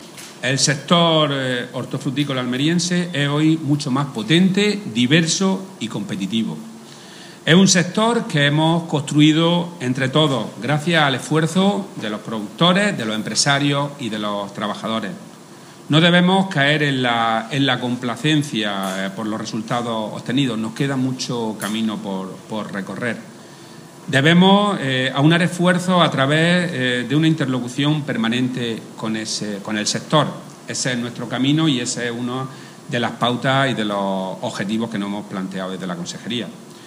Declaraciones de Rodrigo Sánchez sobre el sector hortofrutícola de Almería